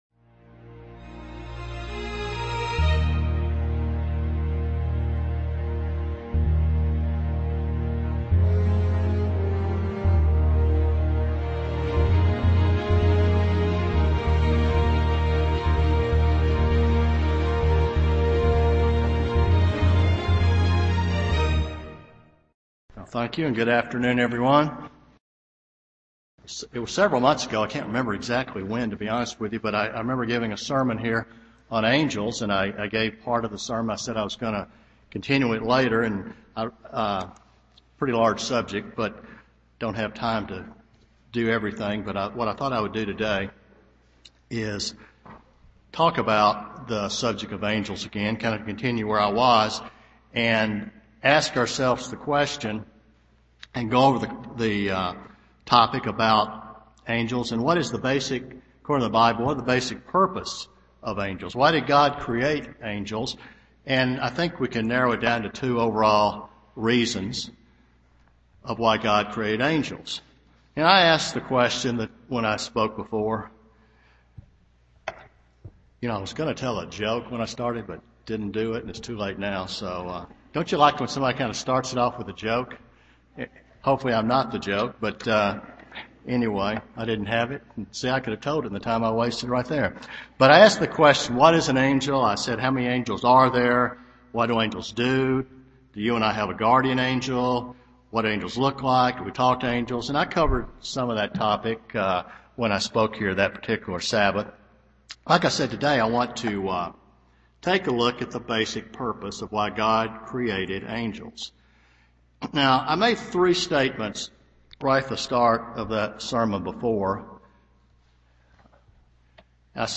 Given in Chattanooga, TN
Hebrews 1:14 UCG Sermon Studying the bible?